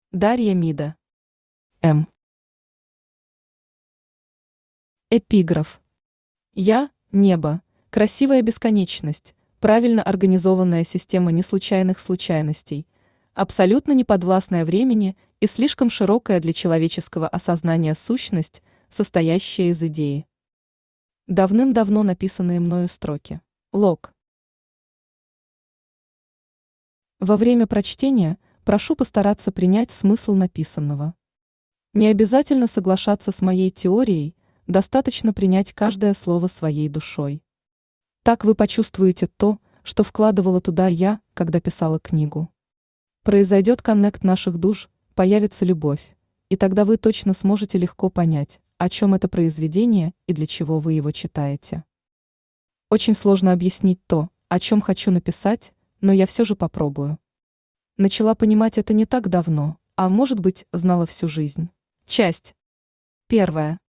Аудиокнига М | Библиотека аудиокниг
Читает аудиокнигу Авточтец ЛитРес.